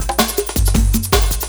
06 LOOP12 -R.wav